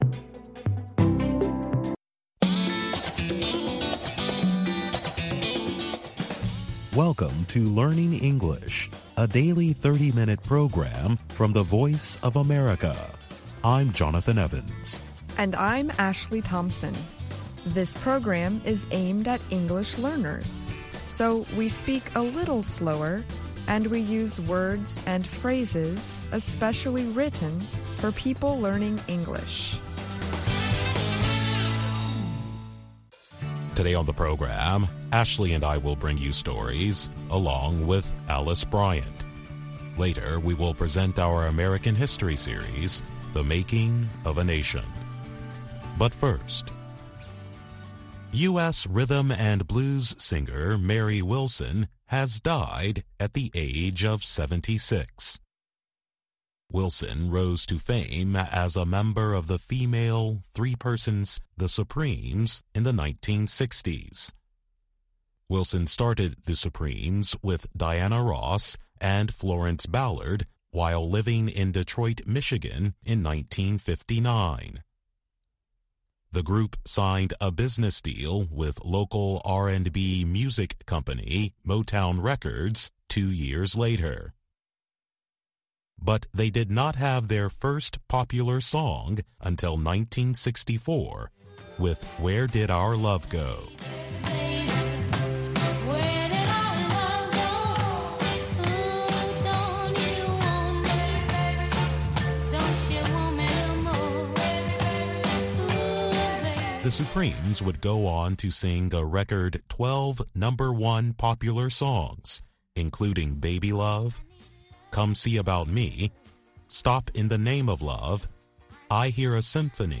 Join veteran journalist Shaka Ssali on Straight Talk Africa every Wednesday as he and his guests discuss topics of special interest to Africans, including politics, economic development, press freedom, health, social issues and conflict resolution.